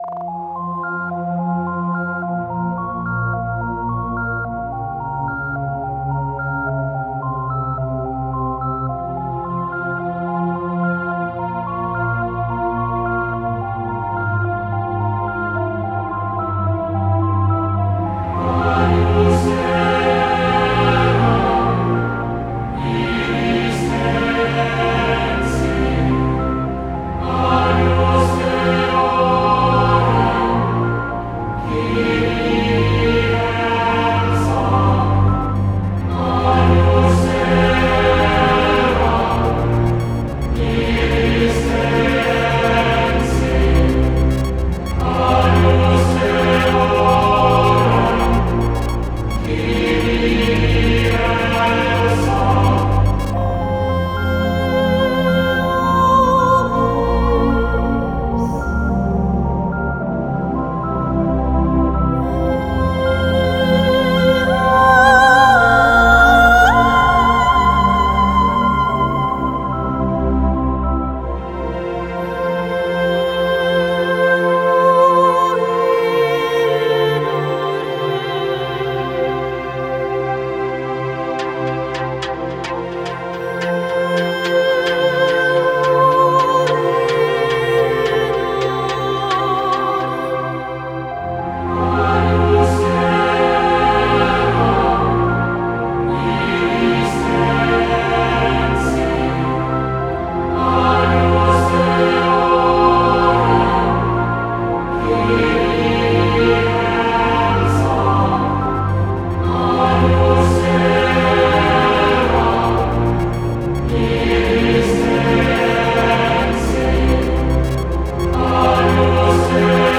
Genre: New Age